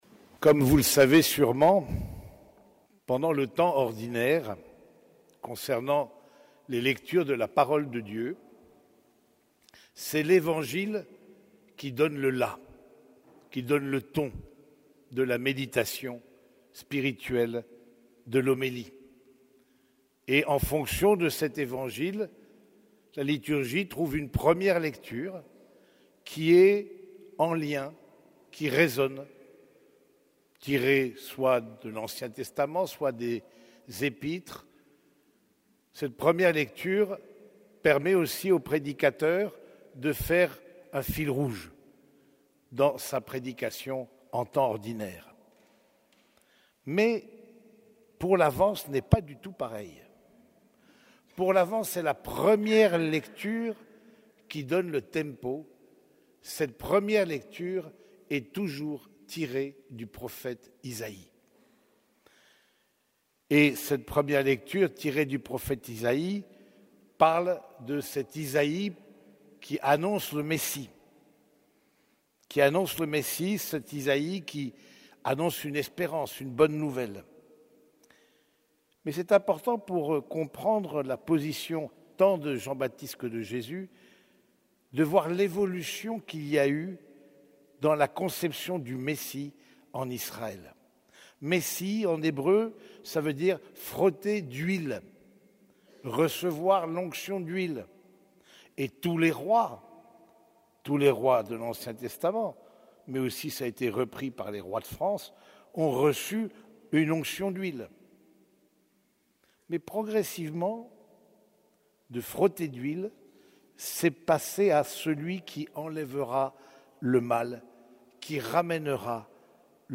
Homélie du Deuxième dimanche de l'Avent